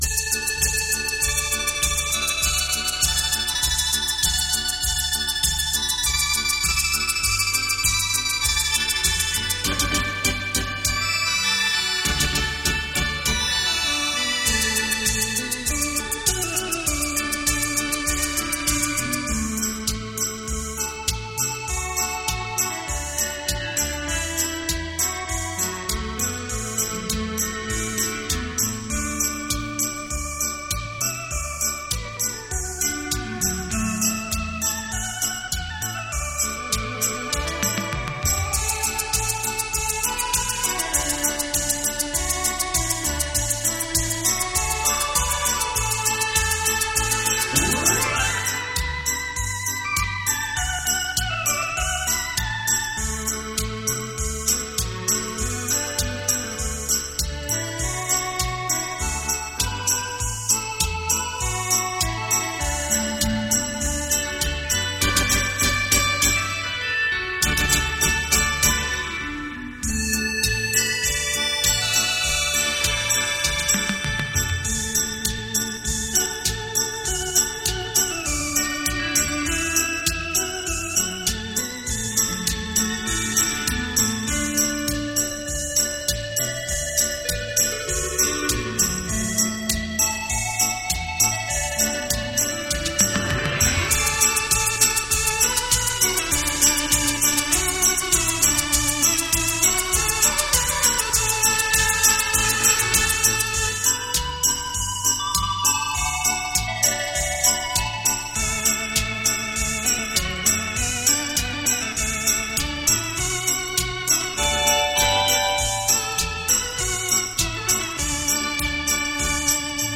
优美的旋律 清脆的音感